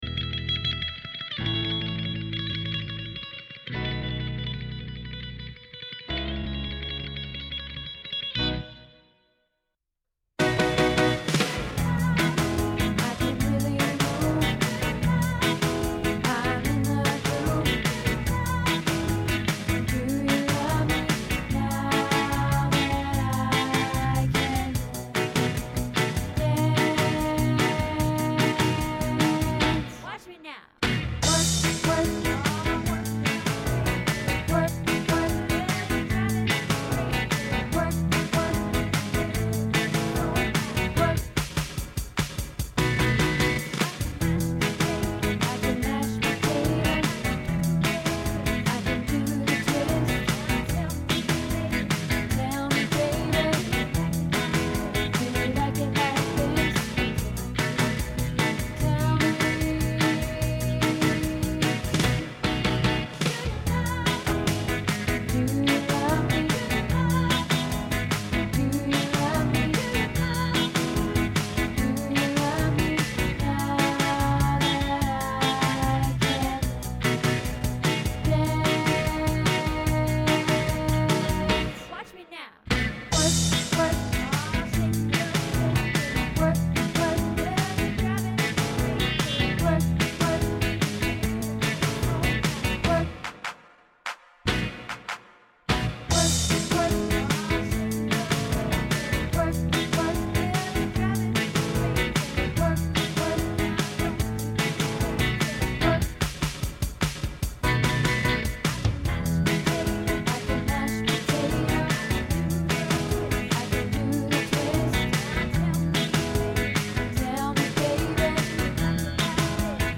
Do You Love Me Bass Final